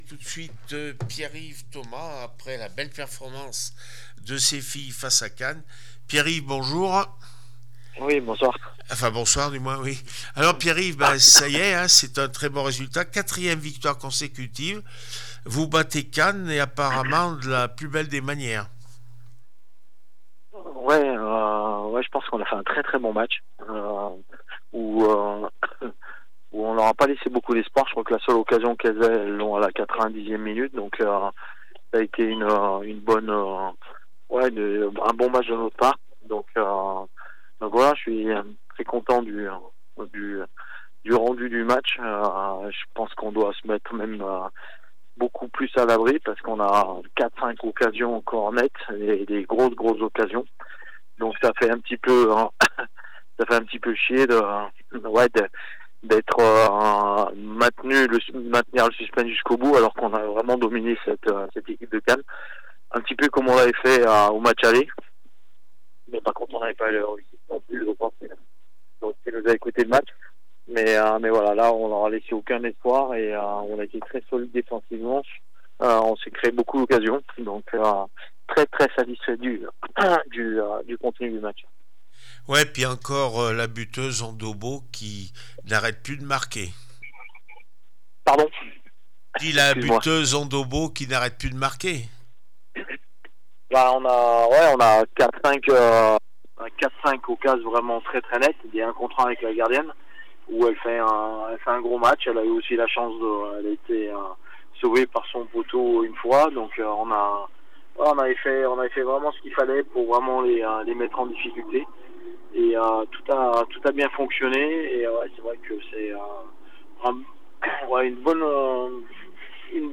14 avril 2025   1 - Sport, 1 - Vos interviews
d3feminine cannes 0-1 le puy foot 43 réaction après match